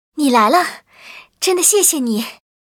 文件 文件历史 文件用途 全域文件用途 Erze_tk_07.ogg （Ogg Vorbis声音文件，长度2.8秒，87 kbps，文件大小：30 KB） 源地址:地下城与勇士游戏语音 文件历史 点击某个日期/时间查看对应时刻的文件。